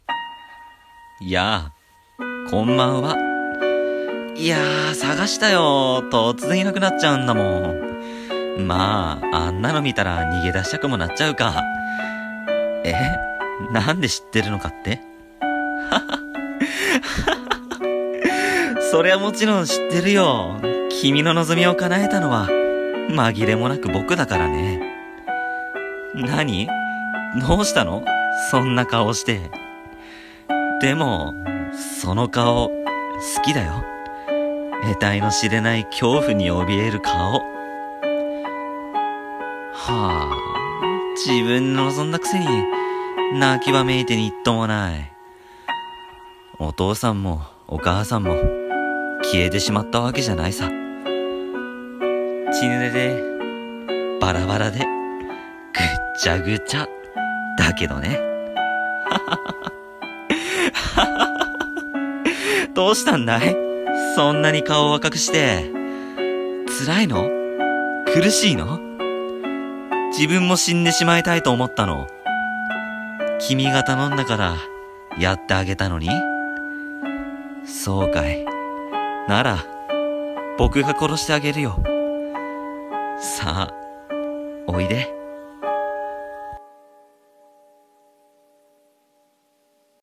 声劇台本『殺し屋』